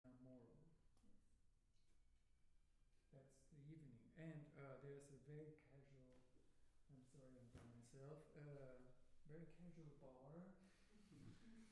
Live from Fridman Gallery: CT::SWaM ExChange014 (Audio)